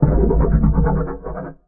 Sfx_creature_spikeytrap_idle_os_03.ogg